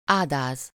Ääntäminen
US : IPA : [fɪə(r)s]